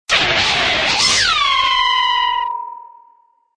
Descarga de Sonidos mp3 Gratis: videojuegos 8.